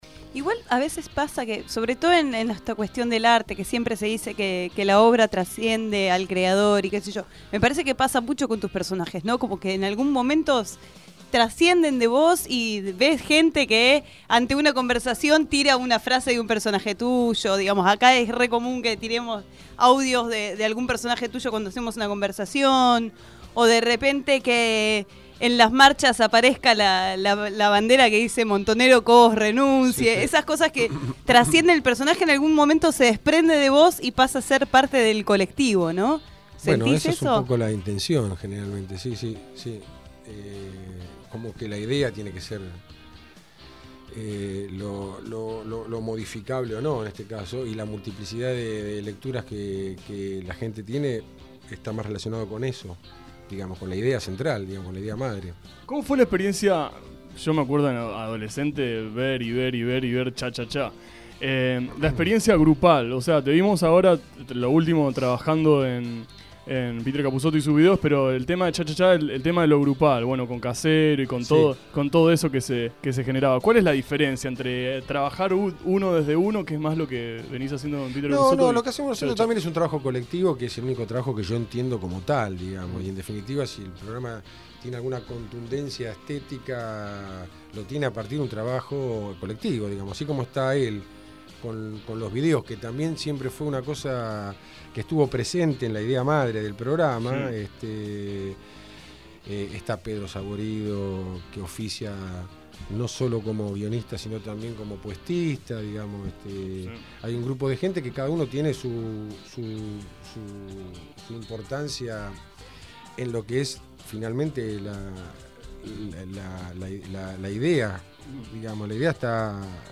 En seis fragmentos la entrevista completa para disfrutarla.